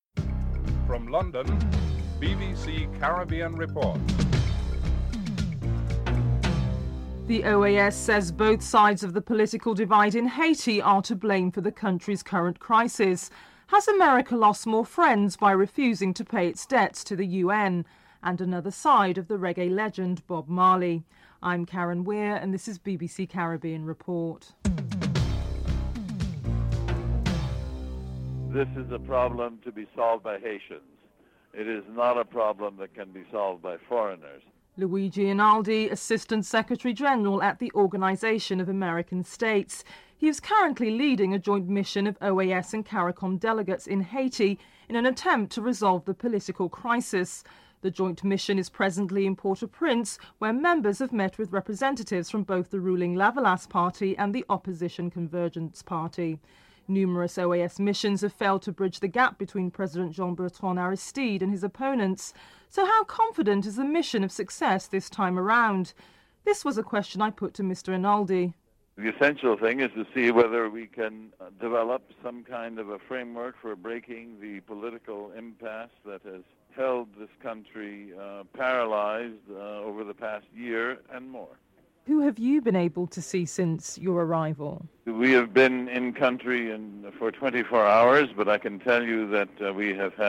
anchor
dc.description.tableofcontents2. OAS says that both sides of the political divide in Haiti are to blame for the country's current crisis. Acting General Secretary of the Organisation of American States Luigi Einaudi is interviewed (00:28-03:34)en_US
Ambassador Patrick Lewis is interviewed (03:35-06:48)en_US